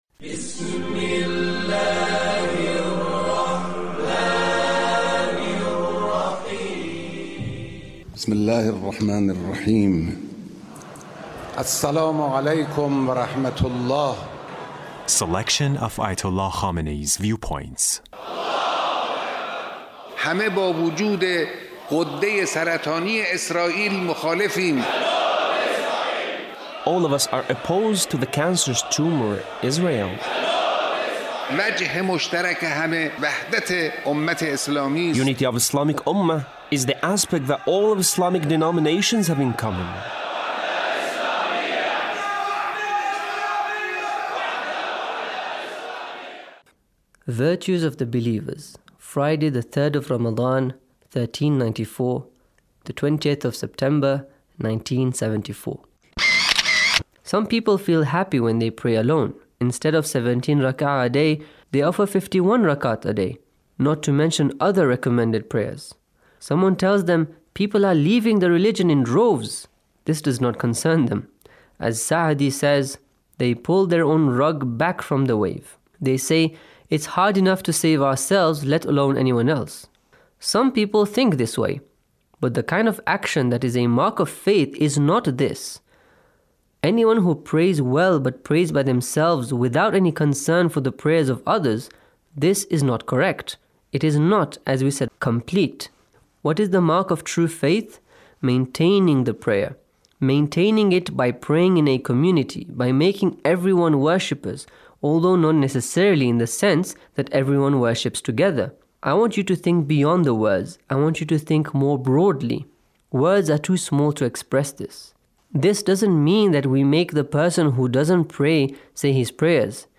Leader's Speech (1540)
Leader's Speech on Taqwa